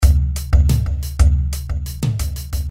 鼓乐循环24
标签： 90 bpm Hip Hop Loops Drum Loops 459.61 KB wav Key : Unknown
声道立体声